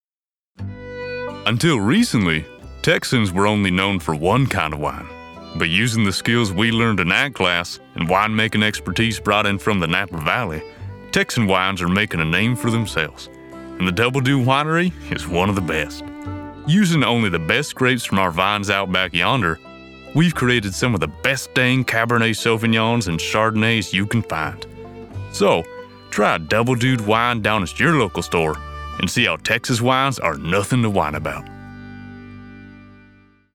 Energetic, Youthful, and Genuine American voice in Tokyo, Japan
Texas Accent
Texas Accent Radio Ad.mp3